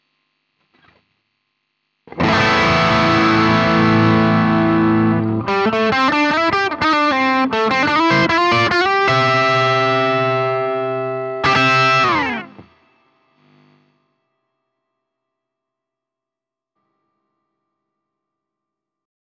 GT -1000coreとGX-1の同じアンプタイプにうっすらホールリバーブをかけた音でサンプルを録ってみました。
いずれもアンプシミュレーターとリバーブのみの音色です。
GX-1 歪み（アンプタイプ　X-HI GAIN）
G-X-1-Drive.wav